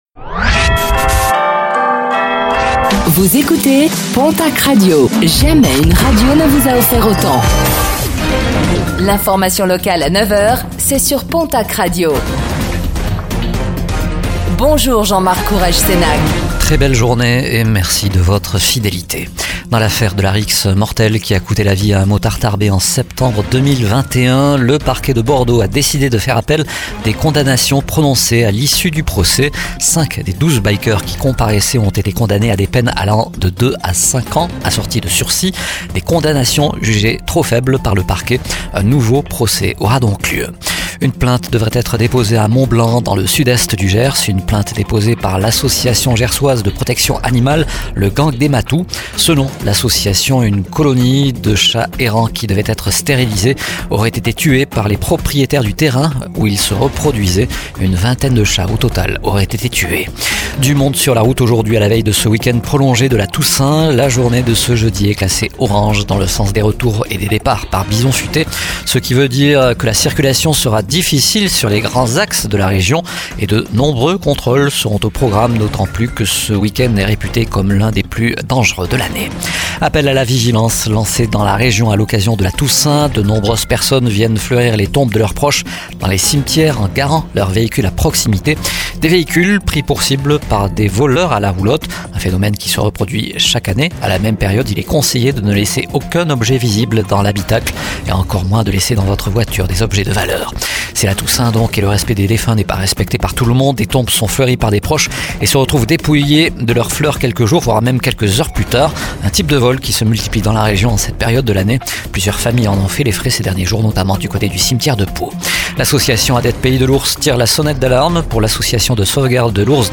Réécoutez le flash d'information locale de ce jeudi 31 octobre 2024